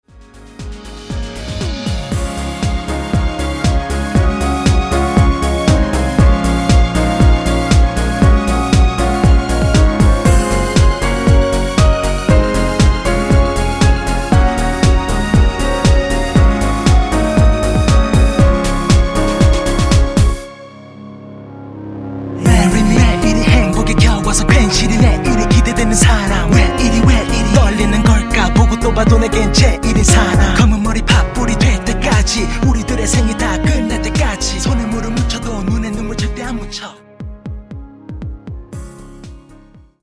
전주가 없는 곡이라 2마디 전주 만들어 놓았습니다.(원키 멜로디MR 미리듣기 참조)
원키에서(-1)내린 랩과 멜로디 포함된 MR입니다.(미리듣기 확인)
Db
앞부분30초, 뒷부분30초씩 편집해서 올려 드리고 있습니다.
중간에 음이 끈어지고 다시 나오는 이유는